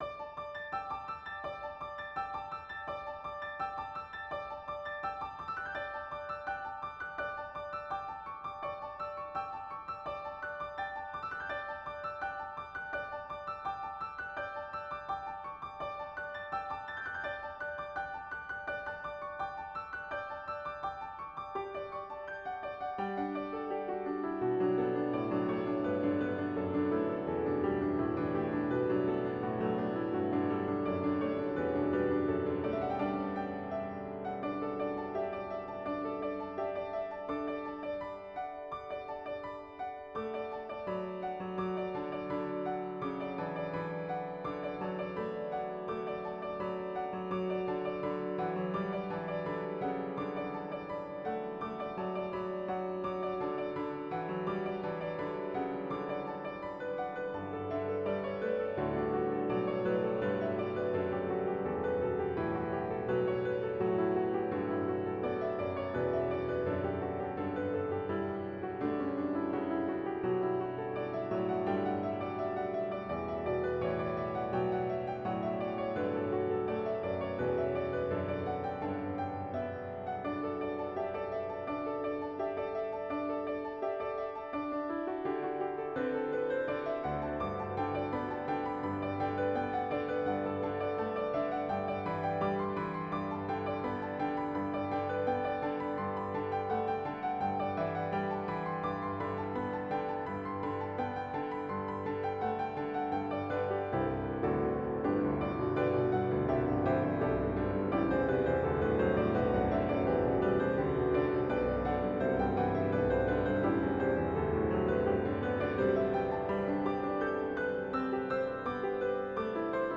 Hymn arrangement